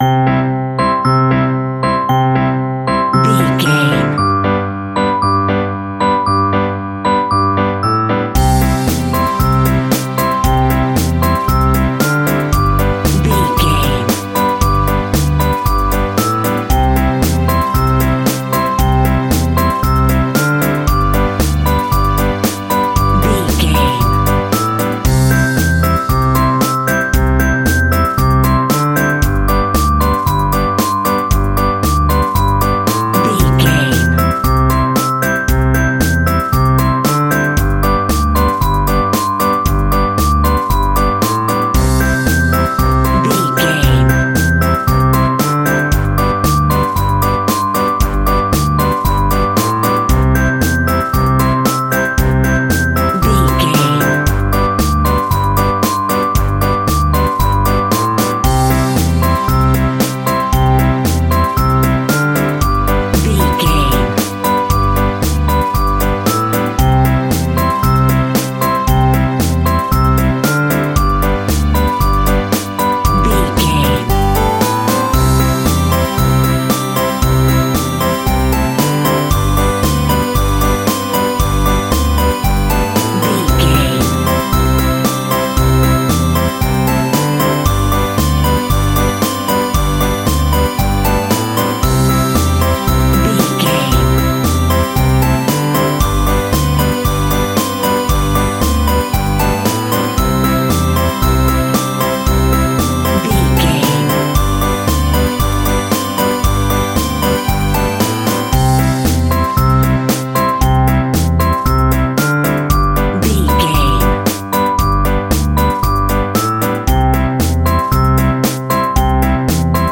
Uplifting
Ionian/Major
childrens music
childlike
cute
happy
kids piano